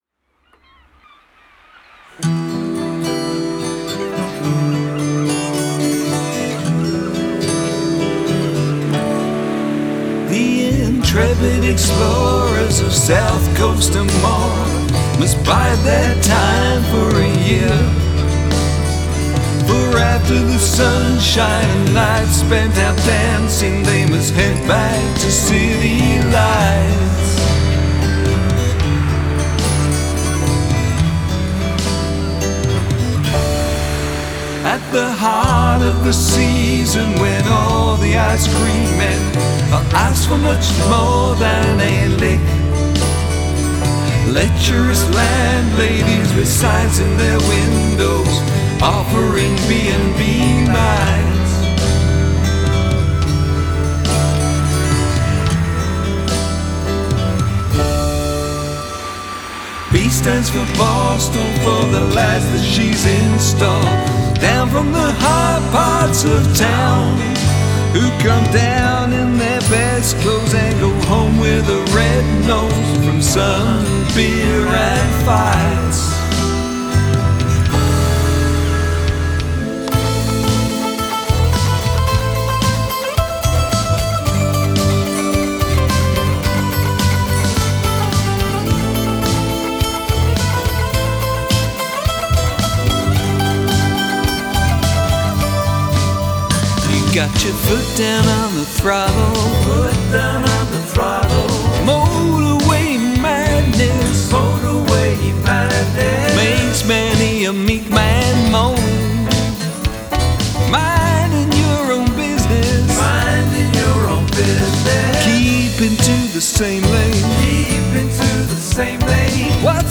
The track starts quietly and builds to a crescendo.
There’s a lot going on.